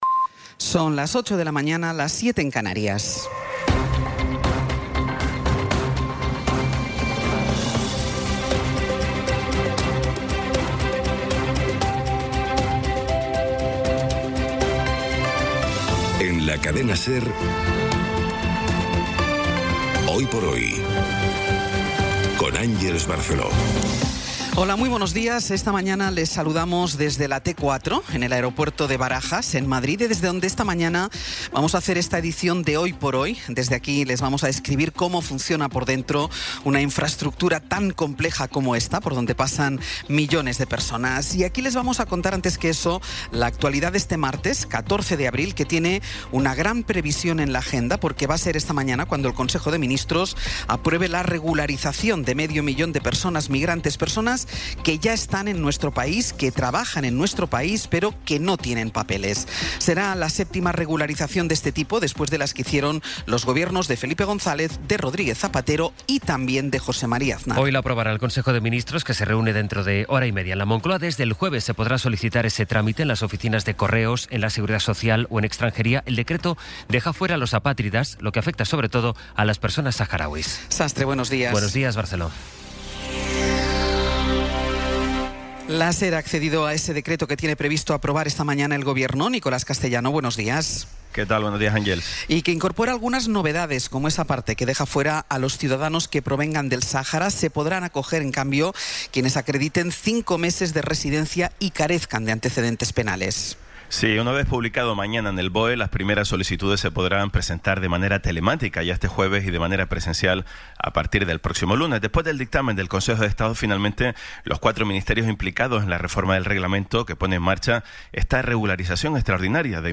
Las noticias de las 08:00 20:25 SER Podcast Resumen informativo con las noticias más destacadas del 14 de abril de 2026 a las ocho de la mañana.